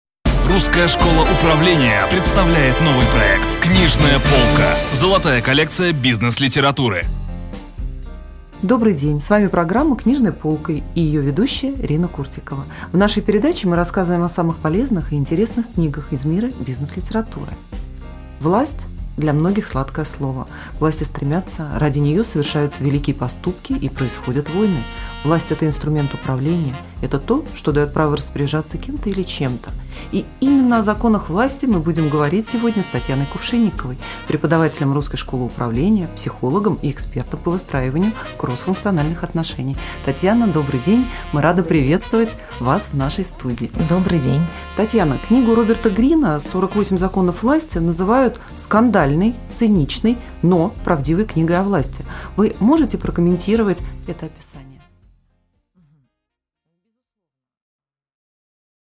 Аудиокнига Обзор книги Р. Грина «48 законов власти» | Библиотека аудиокниг